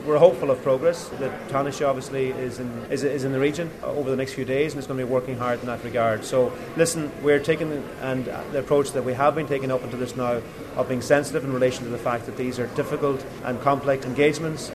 Agriculture Minister Charlie McConologue says this is a complex diplomatic process: